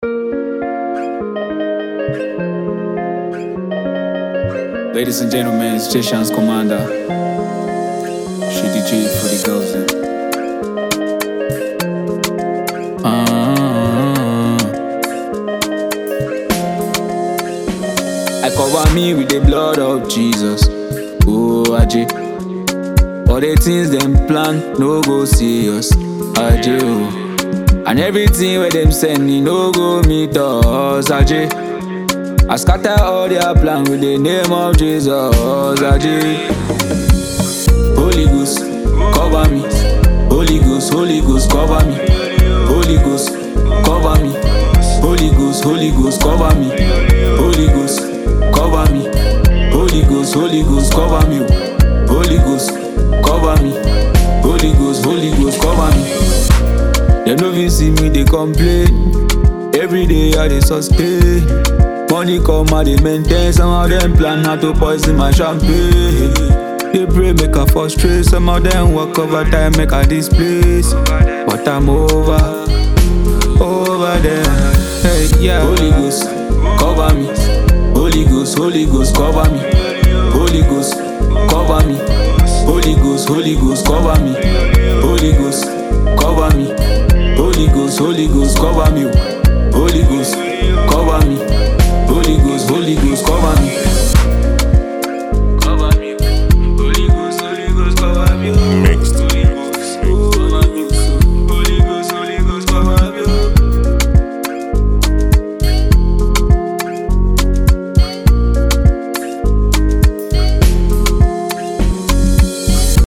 Afro pop
Cool Melody, Positive Message, and groovy vibes all the way.
a fusion of soft vibes in spiritual communeness.